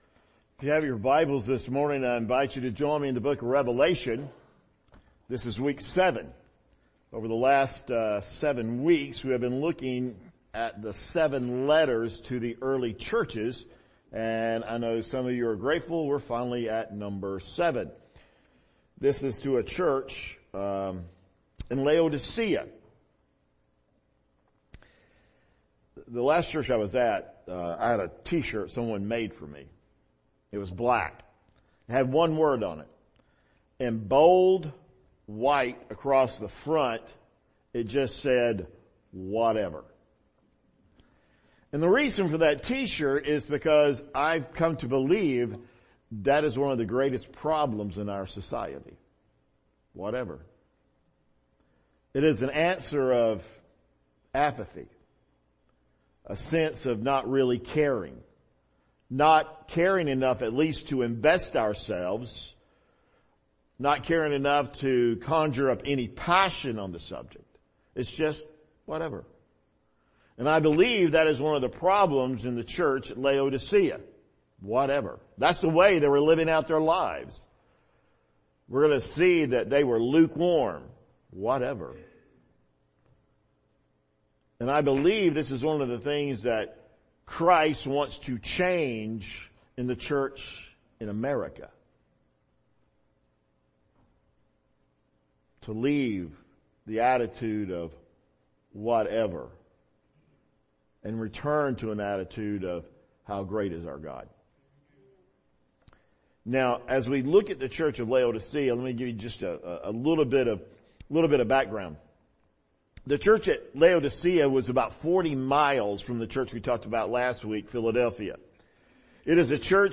November 28, 2021 morning service